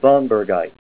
Say SVANBERGITE Help on Synonym: Synonym: ICSD 34345   PDF 39-1361